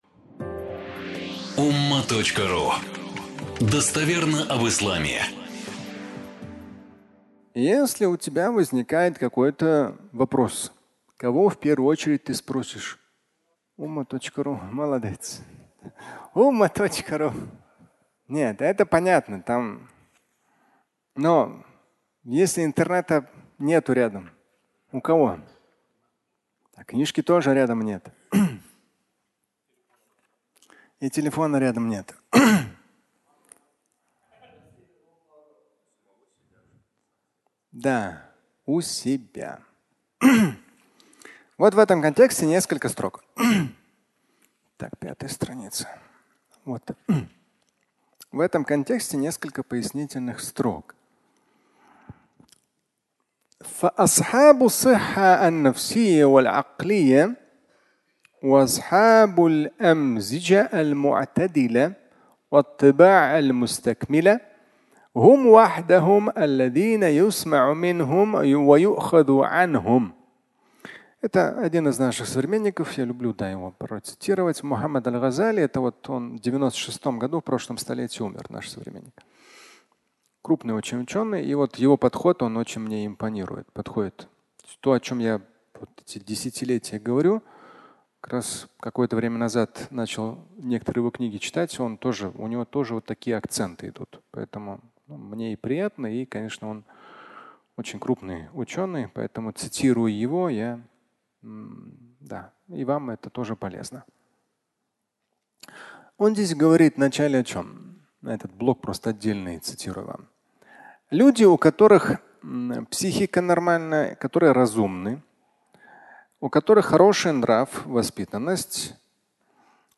(аудиолекция)